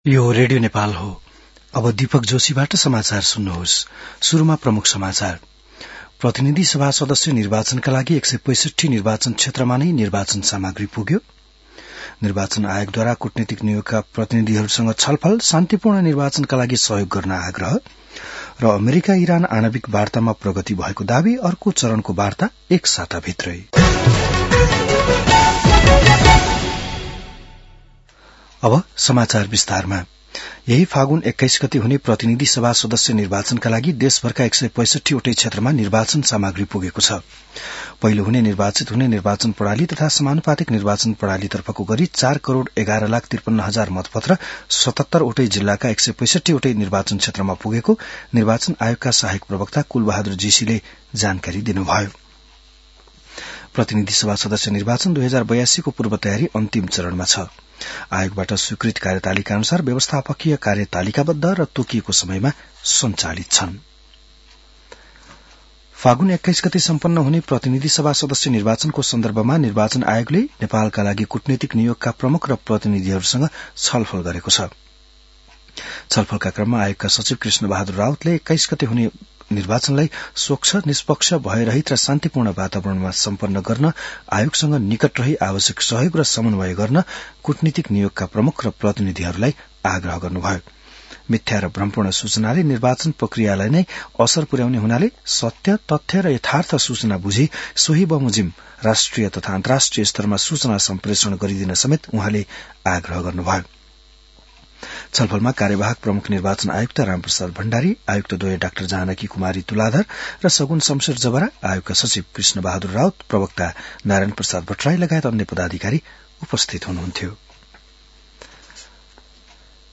बिहान ९ बजेको नेपाली समाचार : १५ फागुन , २०८२